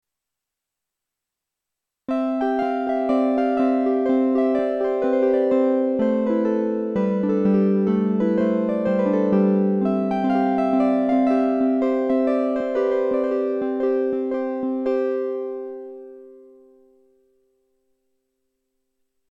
Sauf mention explicite, les enregistrements sont faits sans aucun effet.
p.58 – Sledge : « citharpe », à mi-chemin entre la harpe et la cithare
SLEDGE citharpe